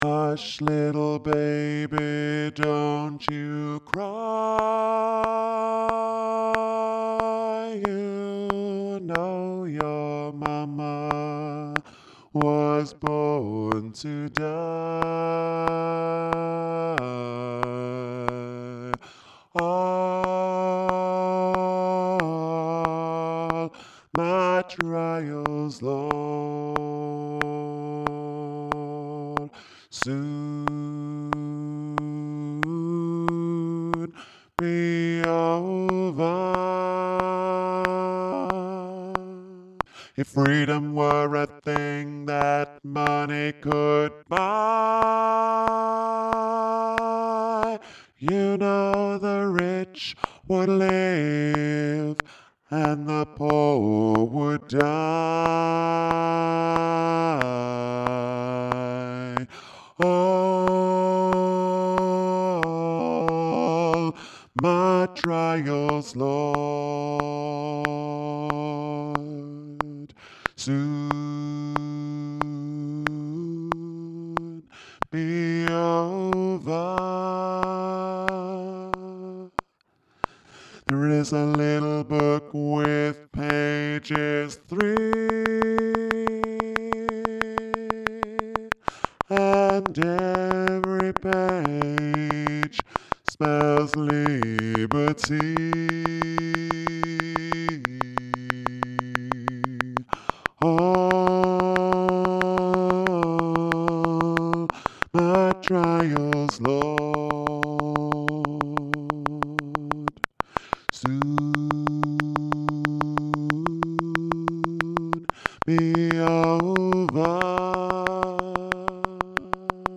all-my-trials-all-my-trials-bass.mp3